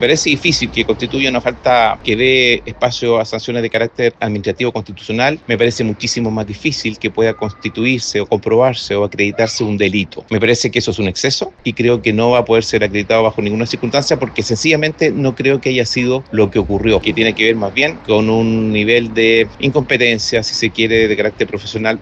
El abogado penalista